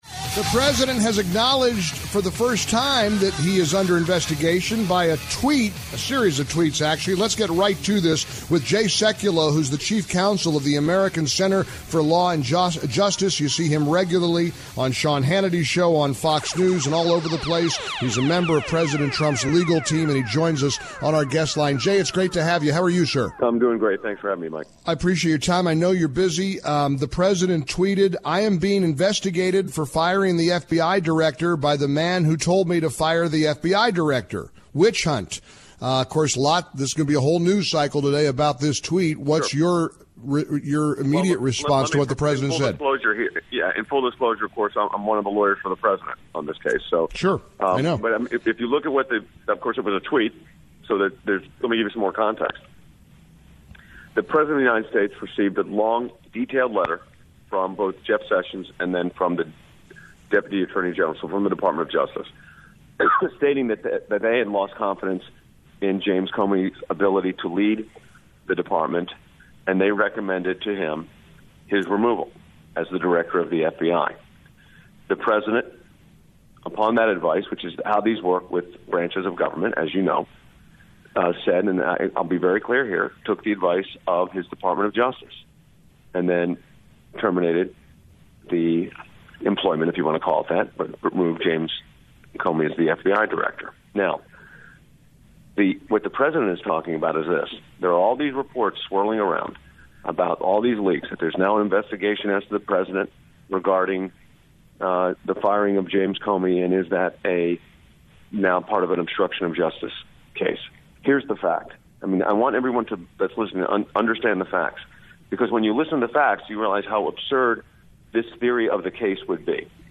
Jay Sekulow joins Mike to discuss President Trump’s obstruction of justice investigation
American Center for Law and Justice Chief Counsel Jay Sekulow, a member of President Trump’s legal circle, joins Mike to discuss the news that the president is being investigated for attempting to obstruct justice.